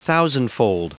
Prononciation du mot thousandfold en anglais (fichier audio)
Prononciation du mot : thousandfold